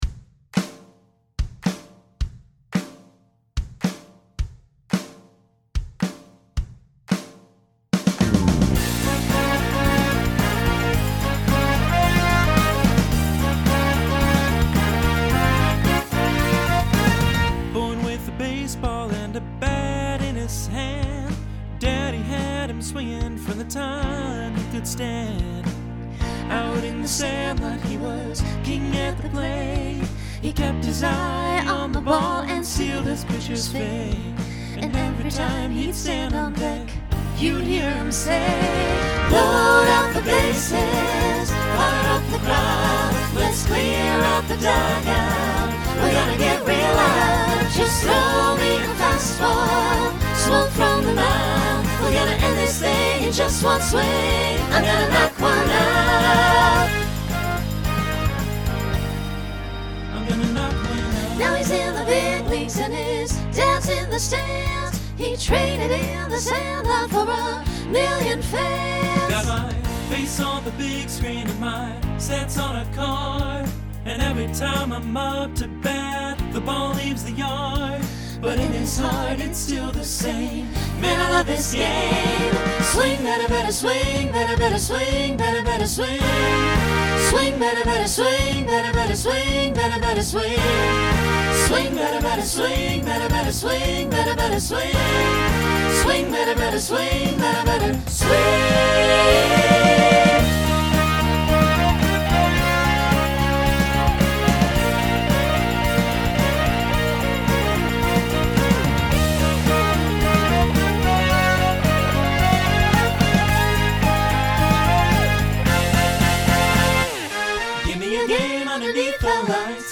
Genre Country , Rock Instrumental combo
Voicing SATB